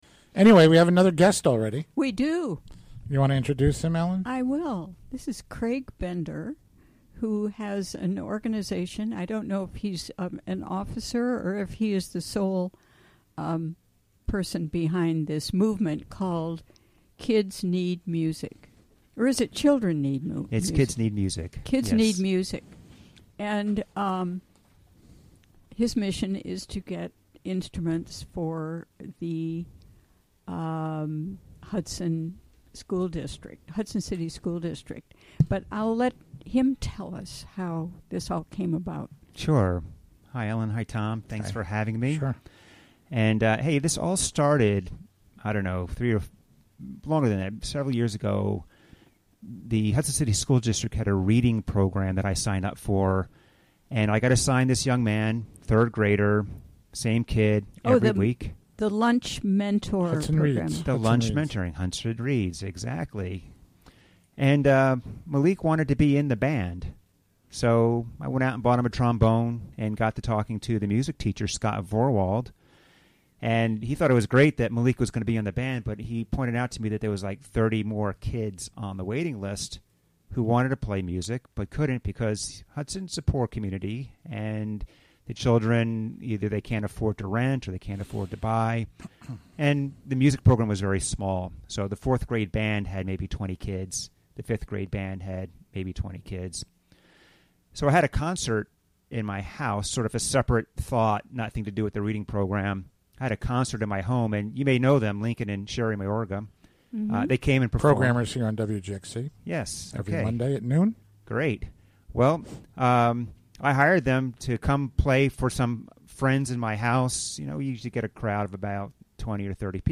Interviewed
Recorded during the WGXC Afternoon Show Thursday, January 12, 2017.